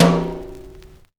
Dusty Tom 02.wav